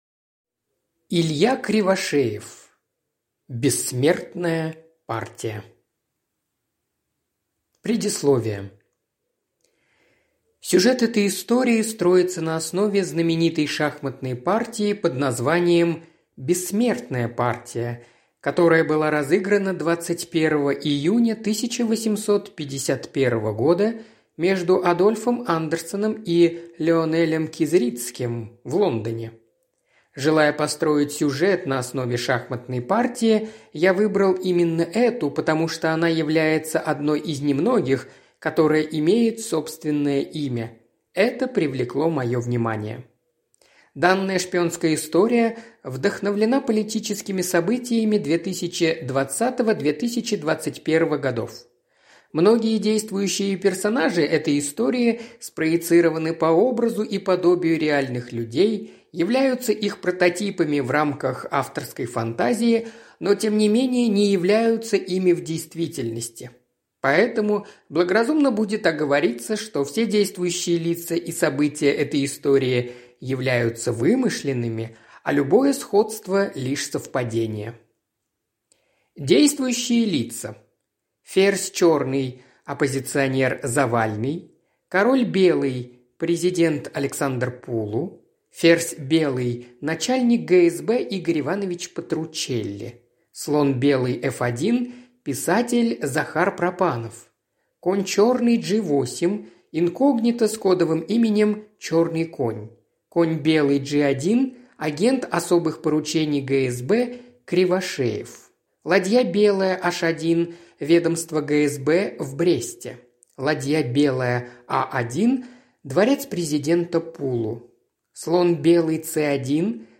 Аудиокнига Бессмертная партия | Библиотека аудиокниг